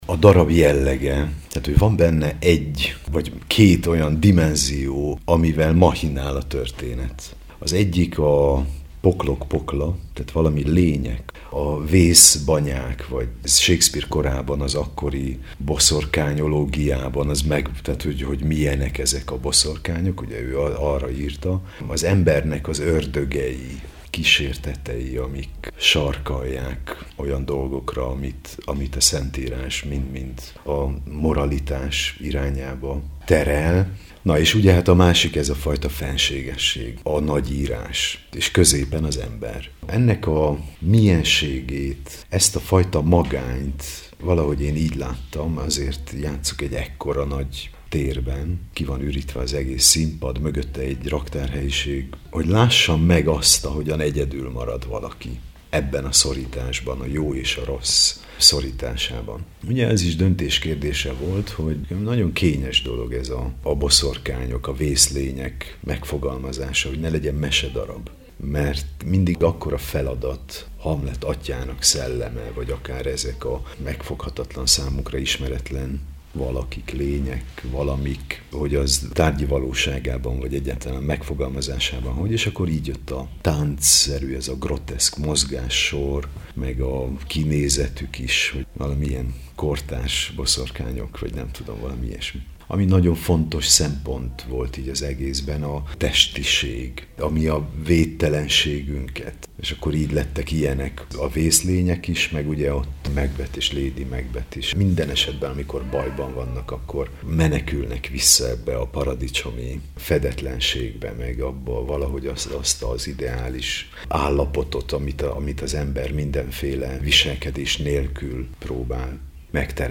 Macbeth, a látvány erejével – beszélgetés a Tompa Miklós Társulat legújabb produkciójáról.